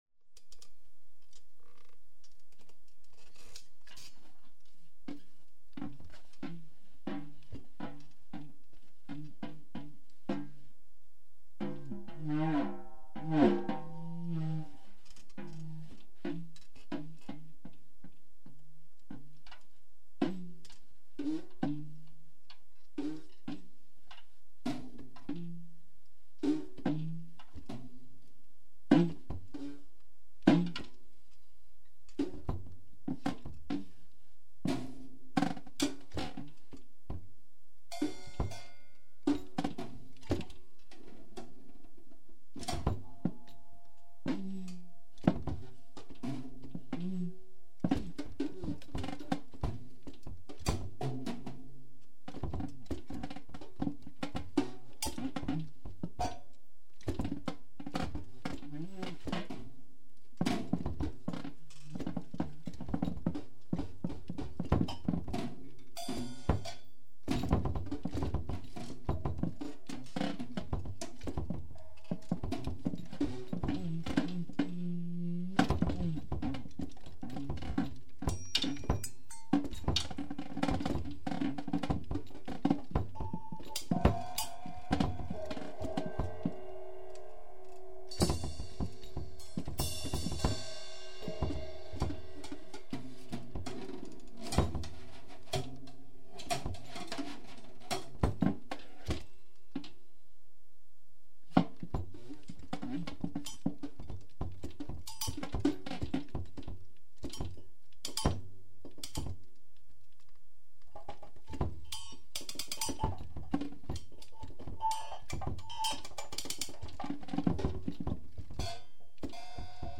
cello
(tenor sax)
(drums)
(guitar)